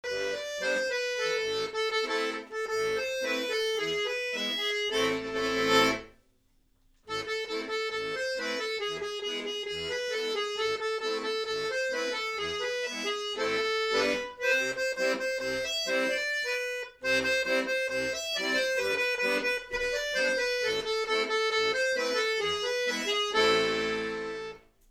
Зацените баян